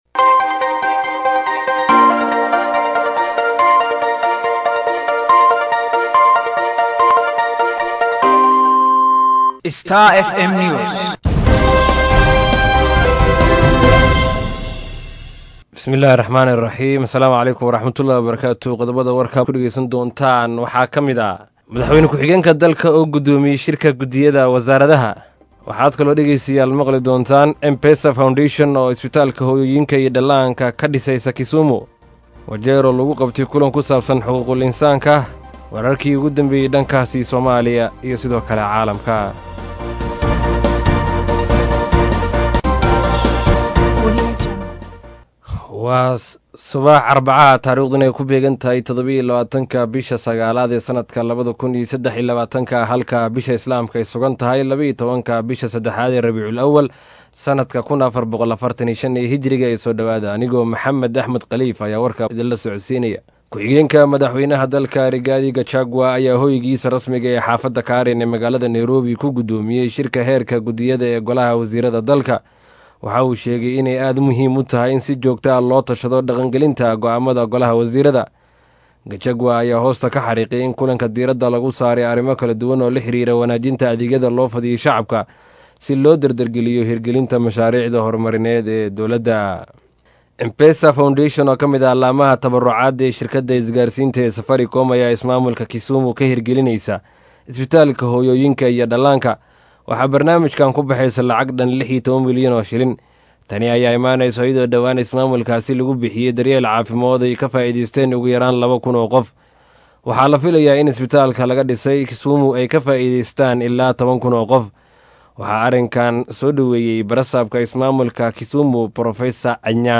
DHAGEYSO:WARKA SUBAXNIMO EE IDAACADDA STAR FM